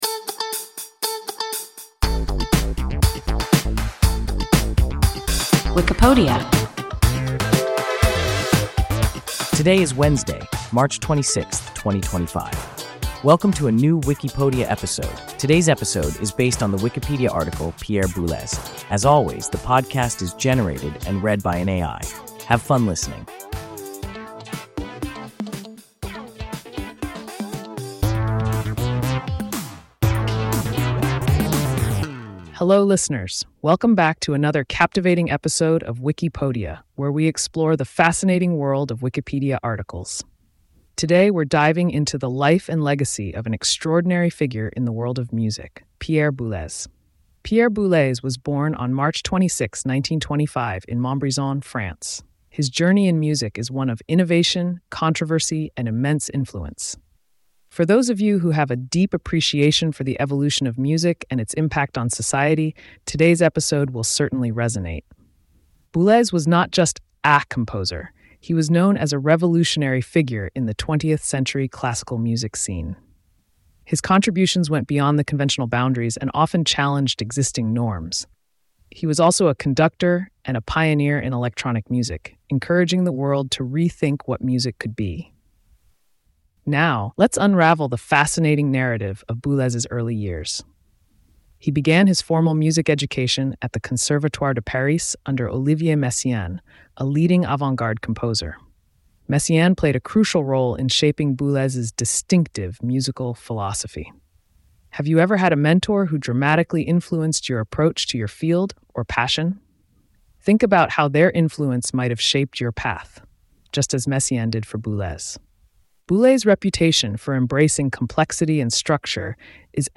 Pierre Boulez – WIKIPODIA – ein KI Podcast